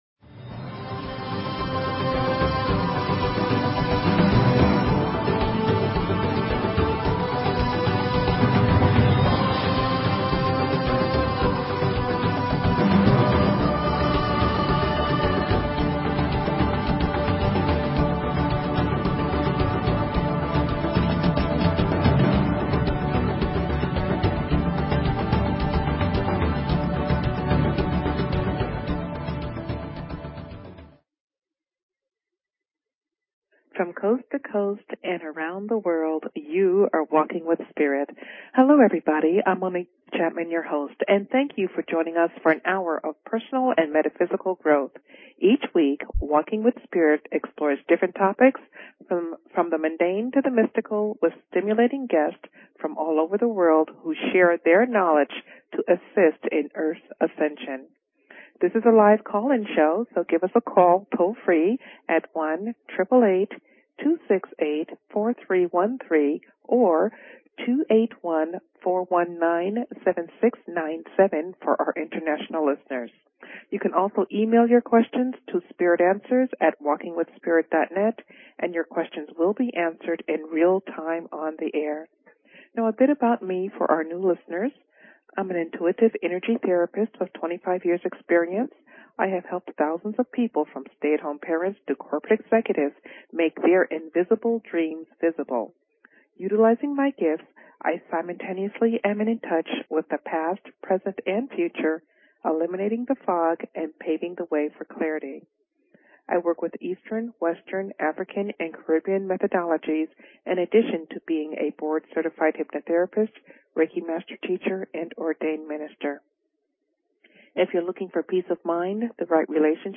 Talk Show Episode, Audio Podcast, Walking_with_Spirit and Courtesy of BBS Radio on , show guests , about , categorized as